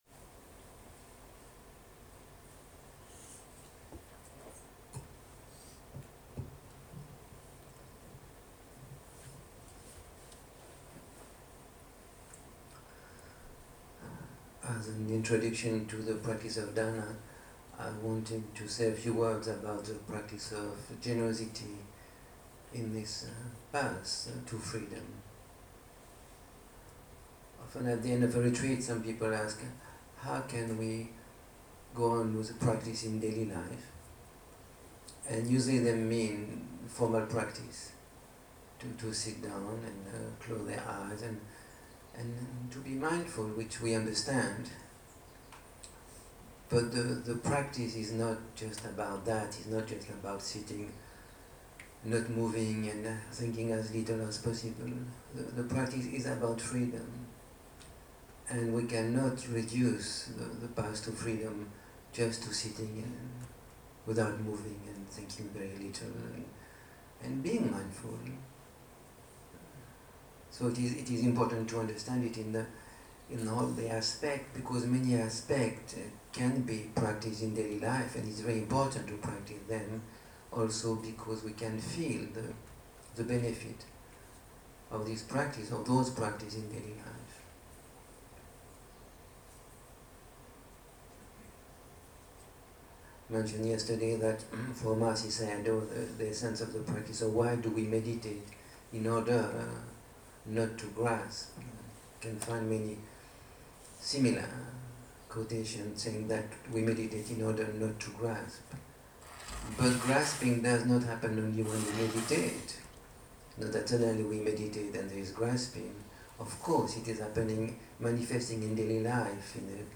שיחת פתיחה של סופ"ש מדיטצית ויפאסנה
Dharma type: Opening talk